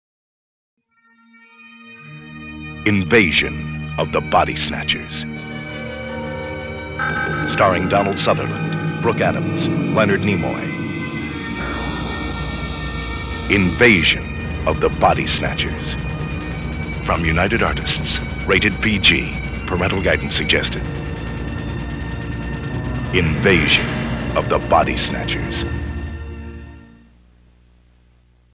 Radio Spots
Here are six spots for the 1978 release…three mono spots and three spots in stereo.